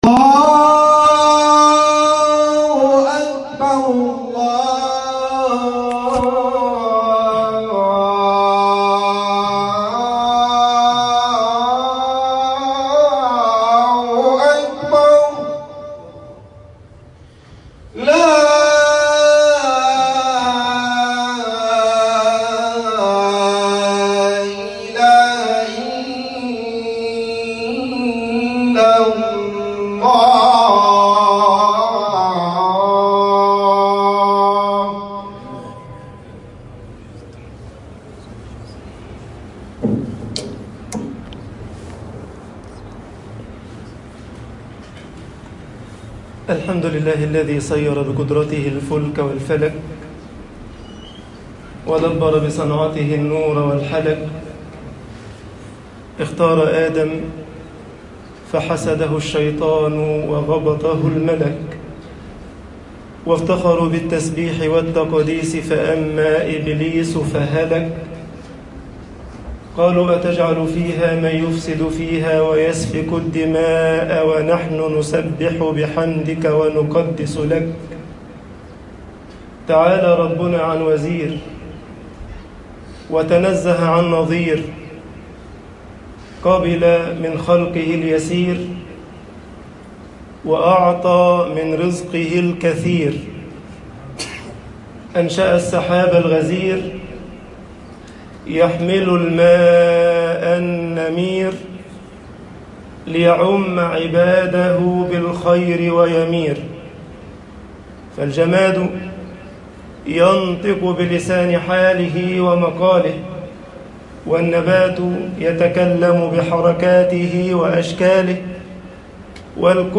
خطب الجمعة - مصر الابتلاء وأجلُّ الغايات